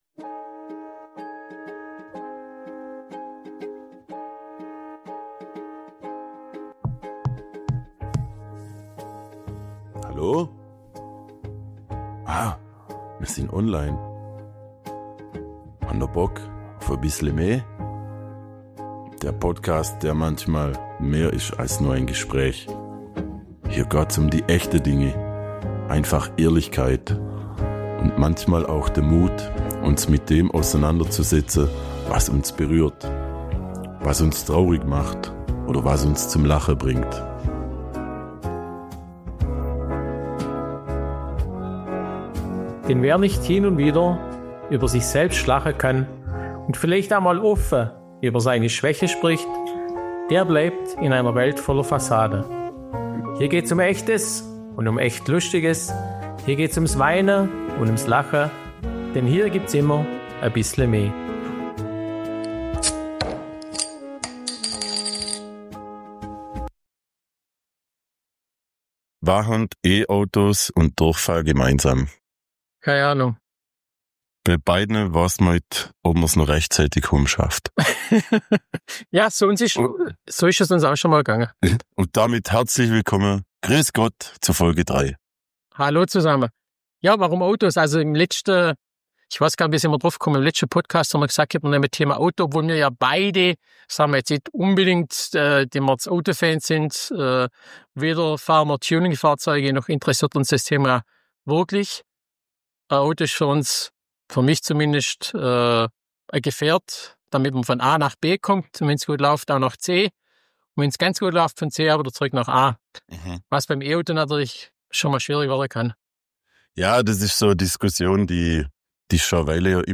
Mit einer gehörigen Portion Mundart, a bissle me Humor und mehr als a Muggaseggele Hirnschmalz schauen wir hinter die Kulissen der bekanntesten – und unbekanntesten – schwäbischen Geistesblitze.